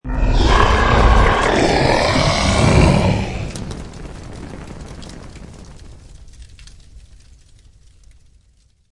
Download Dragon Roar sound effect for free.
Dragon Roar